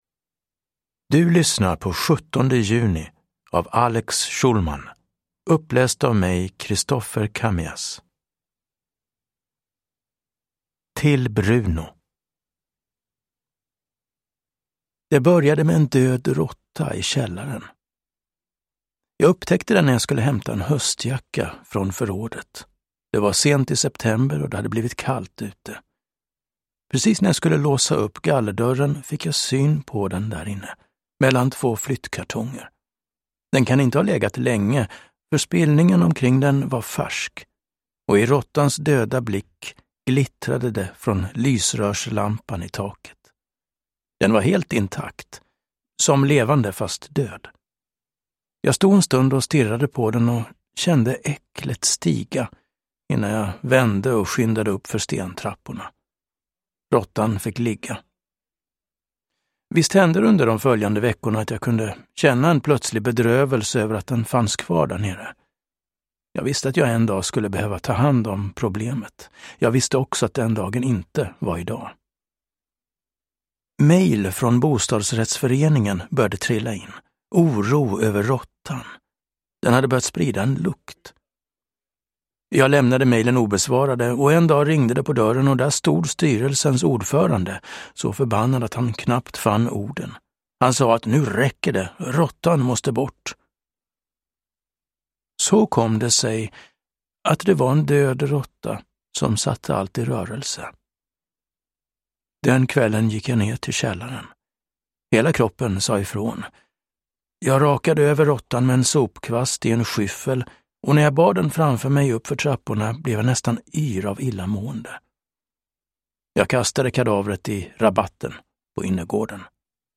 17 juni – Ljudbok